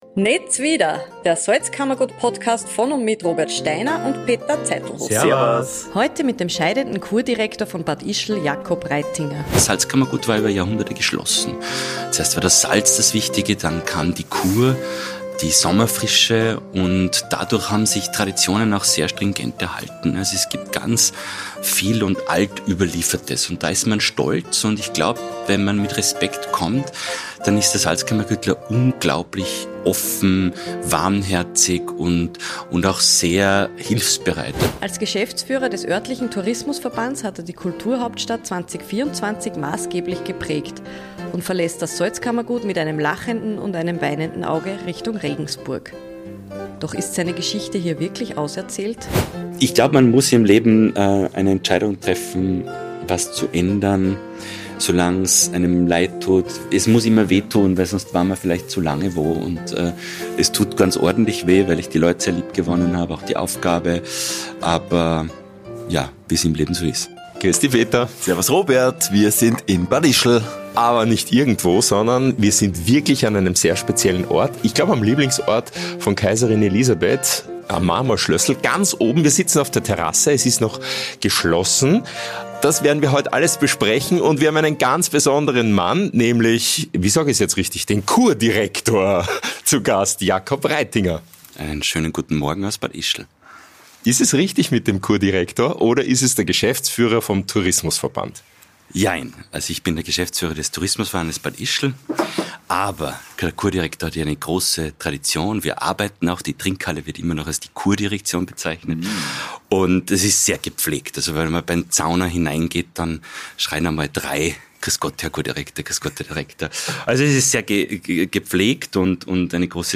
auf der Terrasse des Marmorschlössels der Kaiservilla in Bad Ischl
ein offenes und sehr ehrliches Gespräch